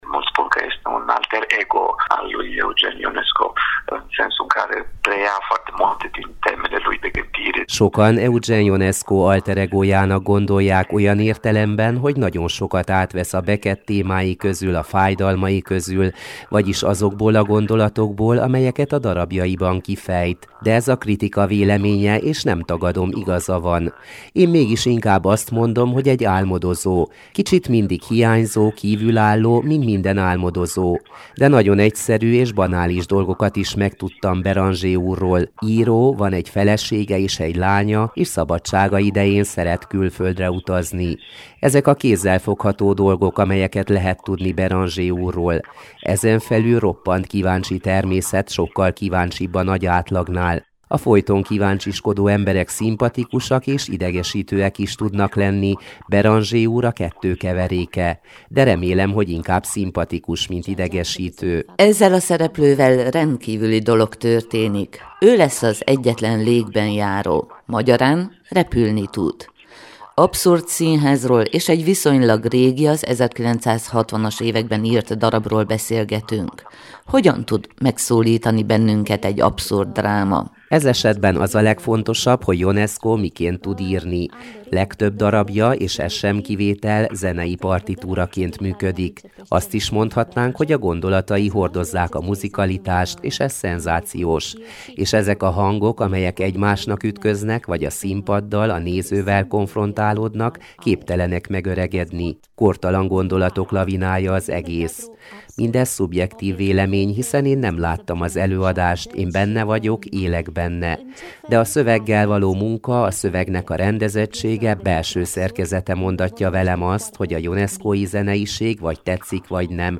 ő mesélt a karakteréről kollégáknak